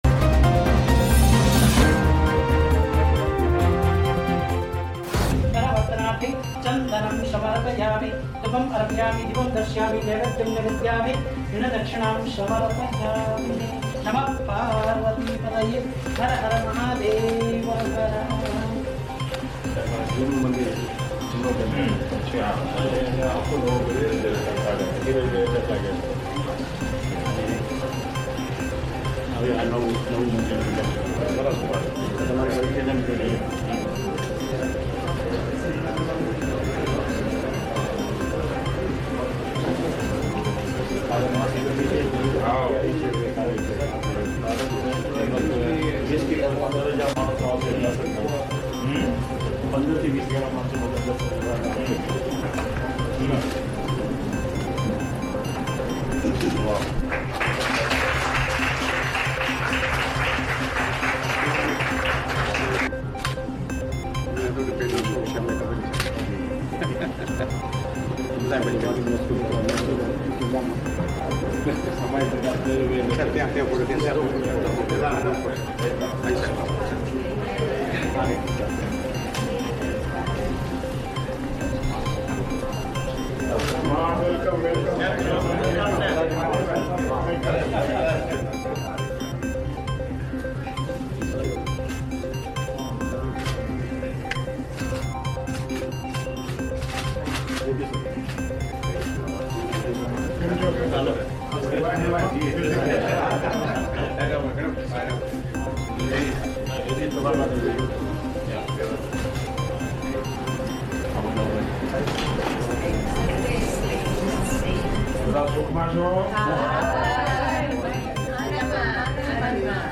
News Report / Know the Shiva temple visited my PM Modi